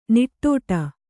♪ niṭṭōṭa